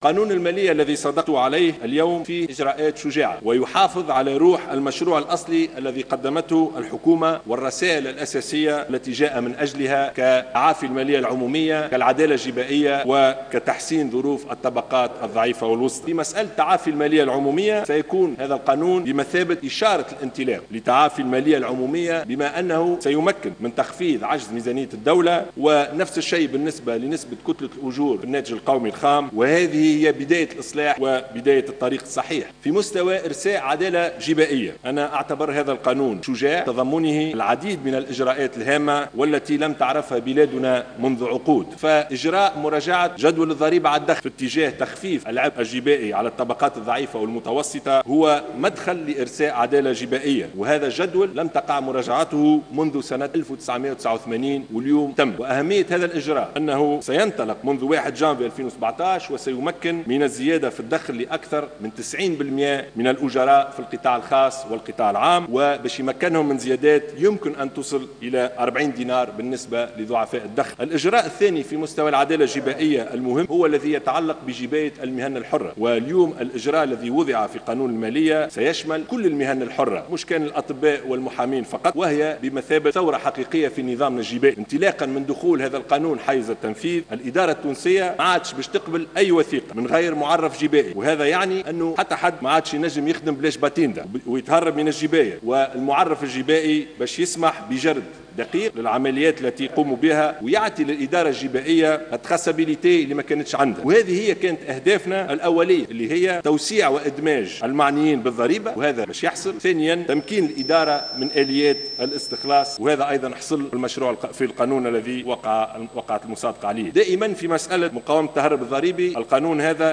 أعلن رئيس الحكومة يوسف الشاهد مساء اليوم السبت بمناسبة كلمة ألقاها أمام النواب في اختتام مناقشة قانون المالية والمصادقة عليه بالأغلبية، إن هناك زيادات منتظرة في أجور جلّ التونسيين بعد قرار مراجعة الضريبة على الدخل.